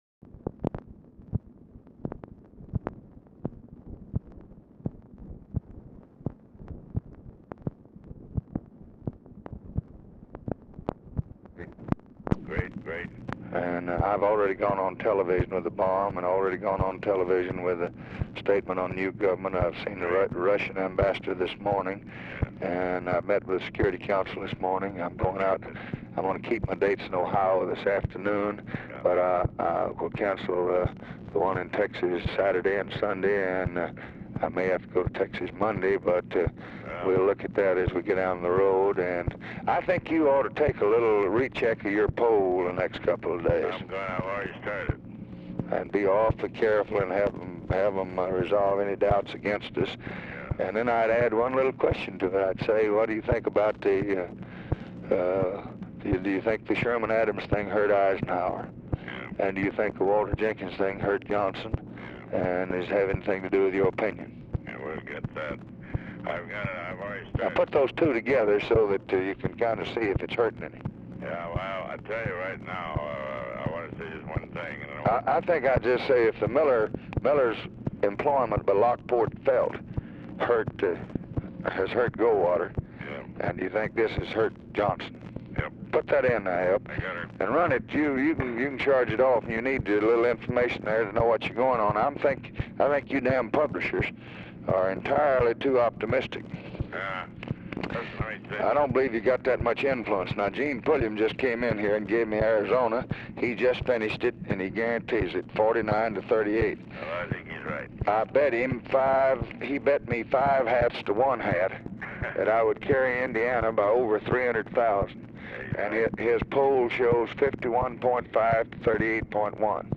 Telephone conversation # 5900, sound recording, LBJ and PALMER HOYT, 10/16/1964, 1:35PM | Discover LBJ
RECORDING STARTS AFTER CONVERSATION HAS BEGUN
Format Dictation belt
Location Of Speaker 1 Oval Office or unknown location